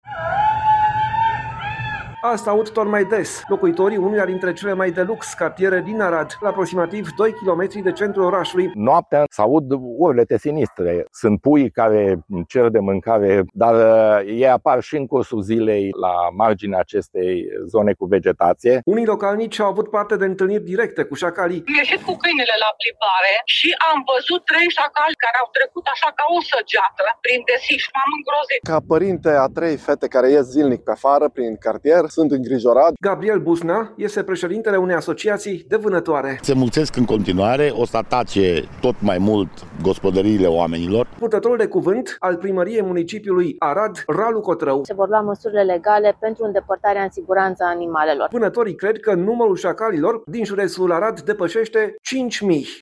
„Noaptea se aud urlete sinistre”, spune un bărbat